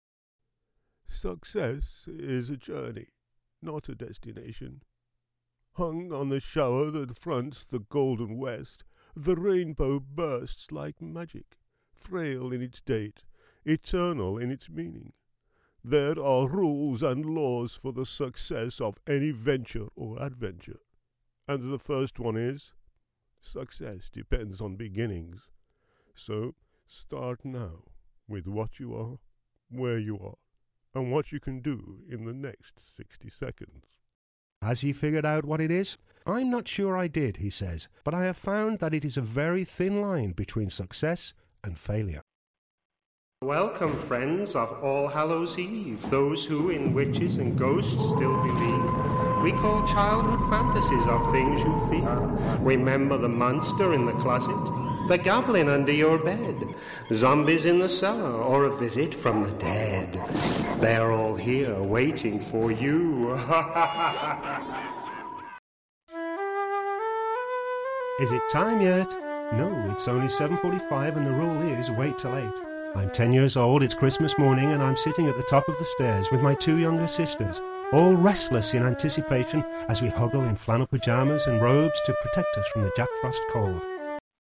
Tags: Voice Talent st.patrick saint patrick Voice actor